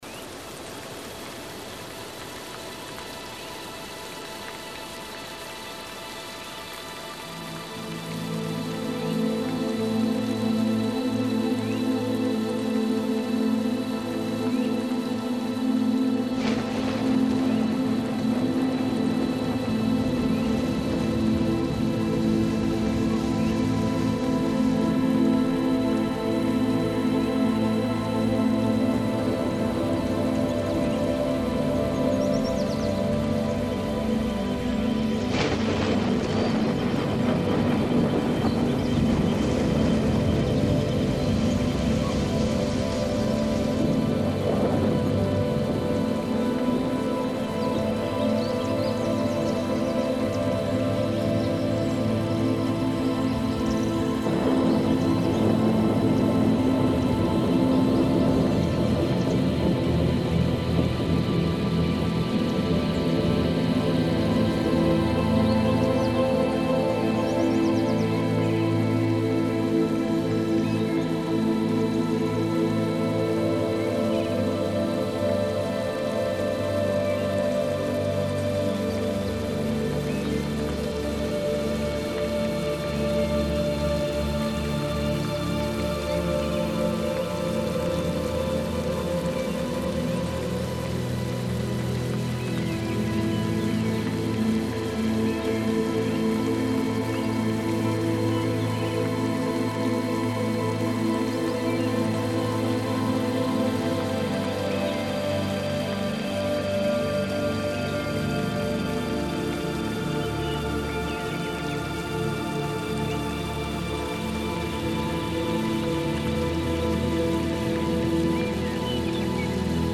Медитативная музыка New age Нью эйдж